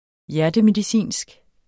Udtale [ ˈjæɐ̯dəmediˌsiˀnsg ]